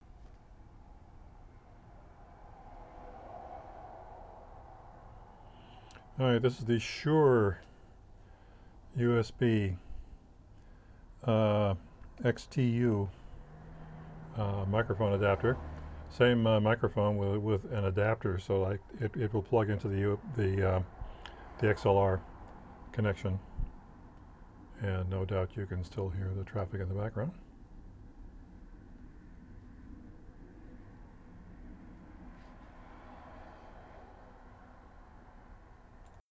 I think I got enough stuff to do a mic check.
Two attachments, one with a Radio Shack 3013 microphone into a C-Media ICUSBAUDIO, and the other an adapted 3013 plugged into a Shure X2U USB digitizer.
It seems the ICUSBAUDIO suffers from the mosquito whine that plagues USB microphone systems.
It doesn’t whine, but it’s much larger and heavier and unless you want to solder your own cables, only works with XLR microphones.